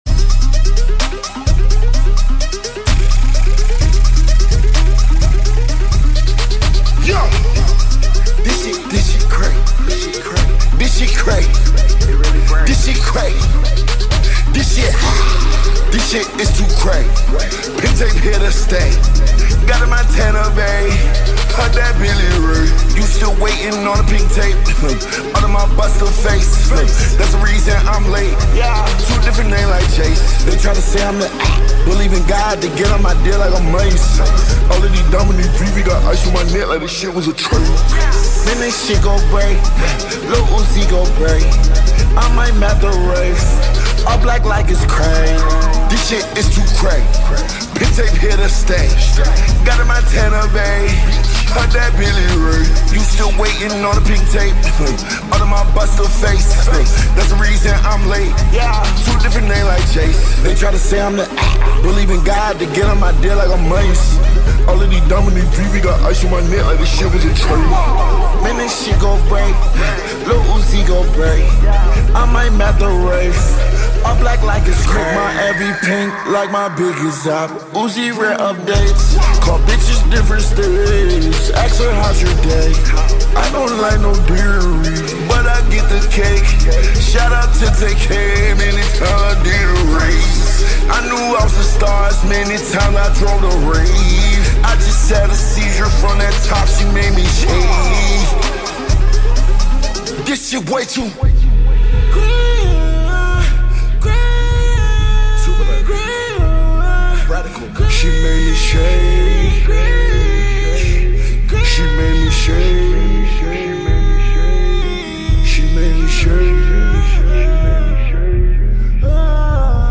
slowed + reverb + bass boosted